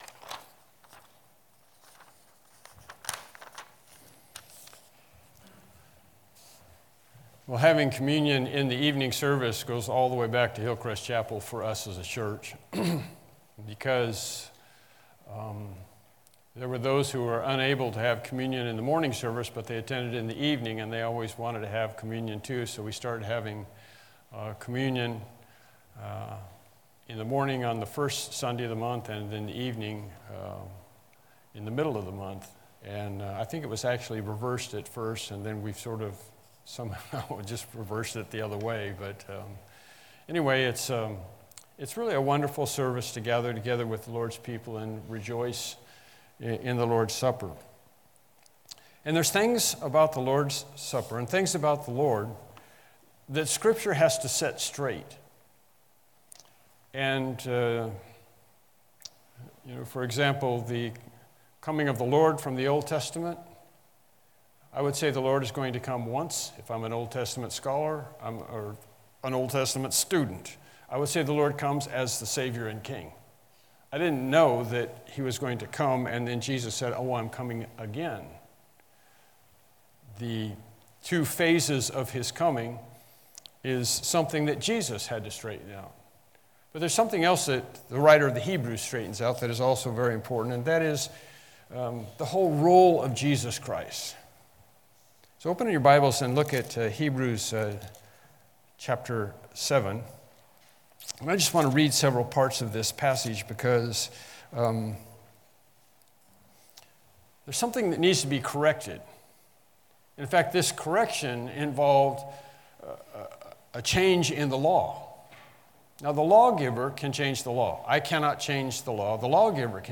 Evening Sermons Passage
Evening Worship Service Topics